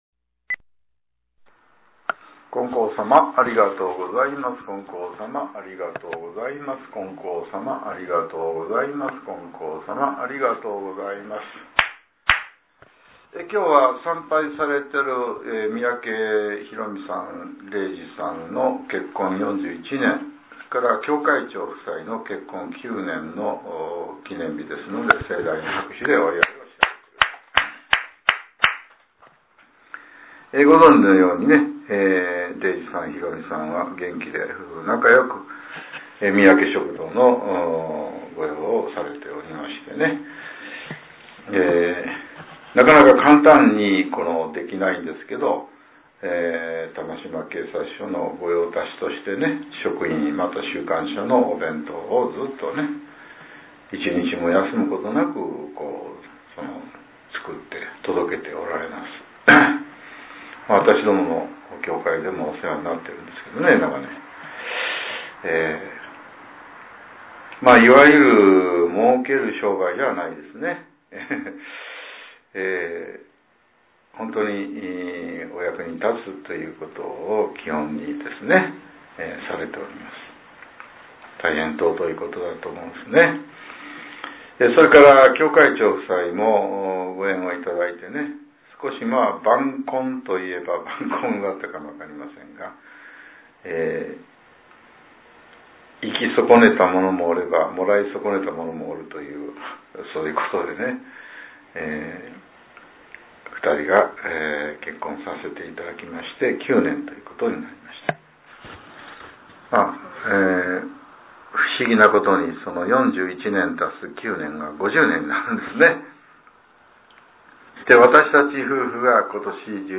令和６年１１月６日（朝）のお話が、音声ブログとして更新されています。